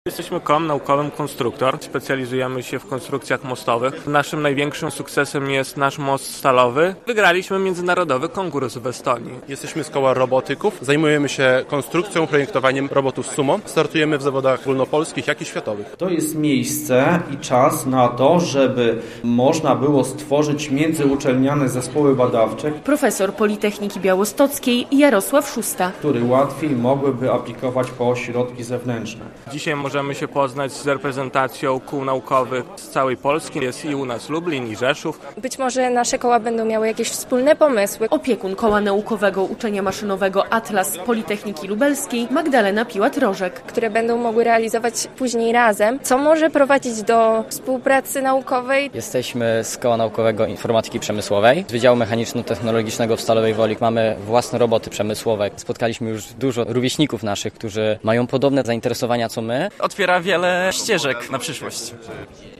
Szósta edycja Konferencji Kół Naukowych - relacja